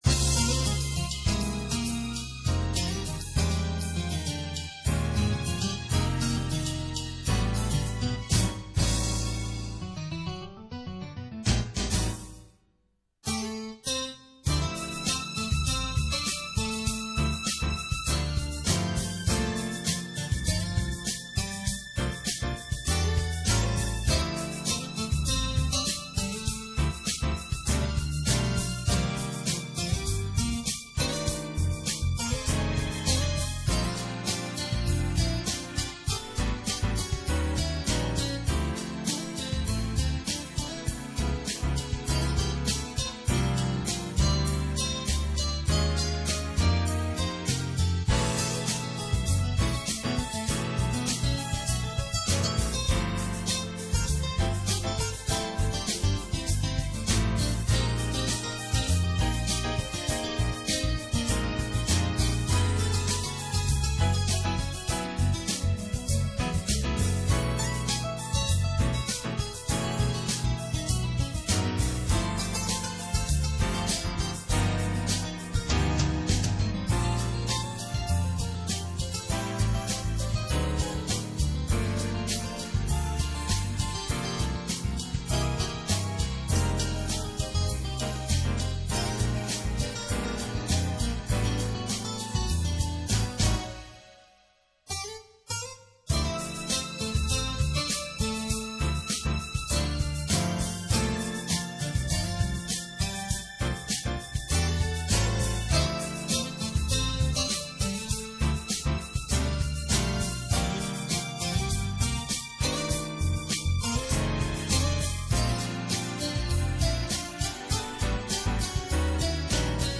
Rock - Pop: